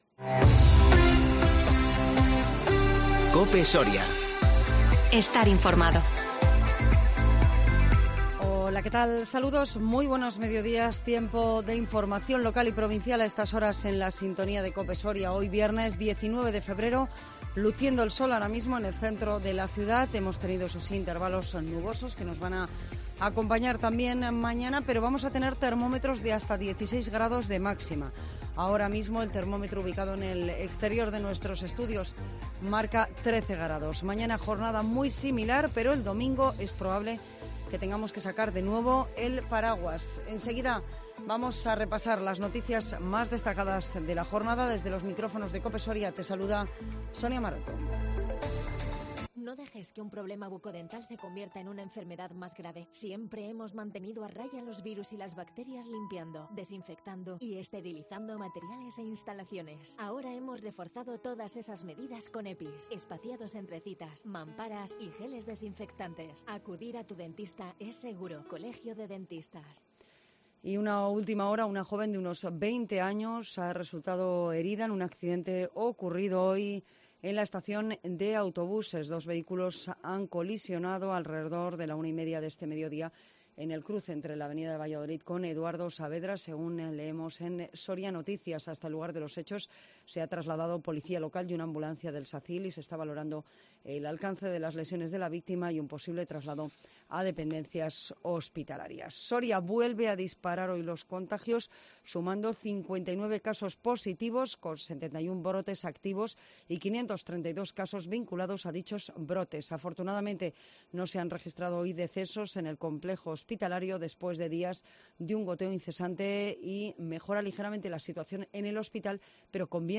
INFORMATIVO MEDIODÍA 19 FEBRERO DE 2021